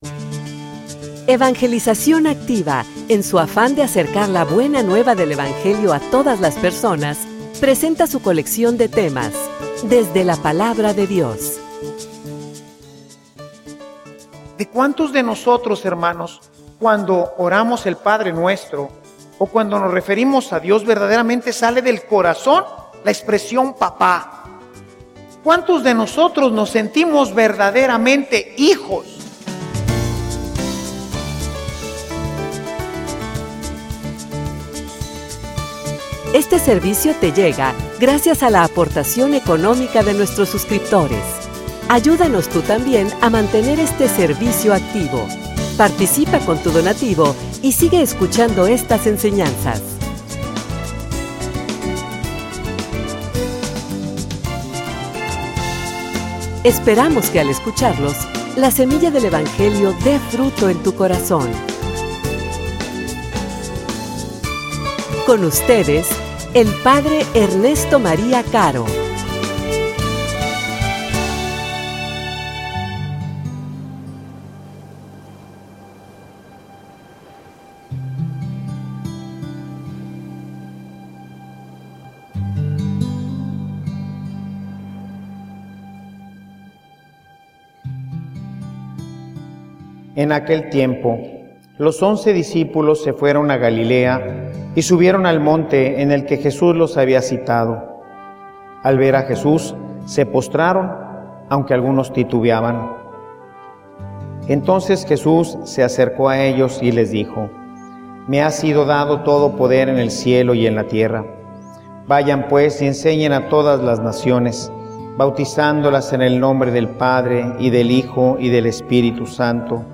homilia_Cuando_oren_digan_Padre.mp3